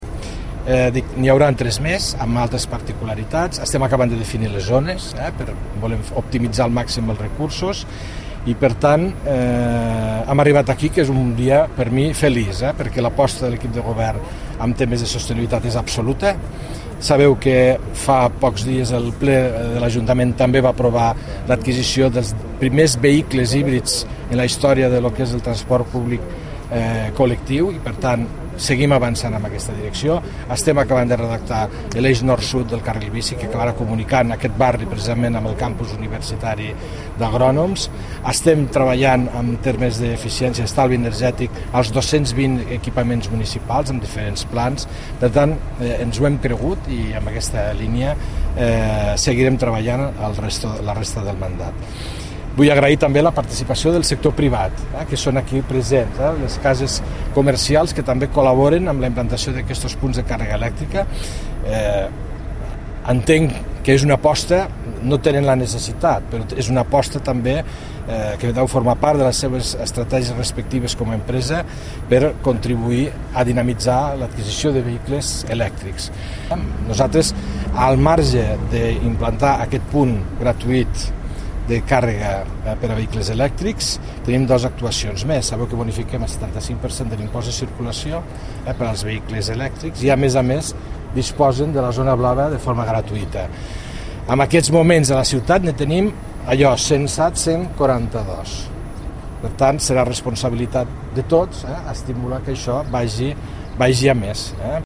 tall-de-veu-f-larrosa